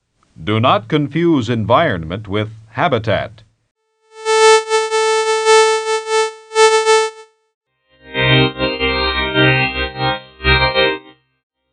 “envelope follower” is another possibility, but the result would be monotone, (whereas kazoo can vary in pitch) /uploads/default/original/2X/5/591acbd12a1d12dd761a44cc185889b1c63103d5.mp3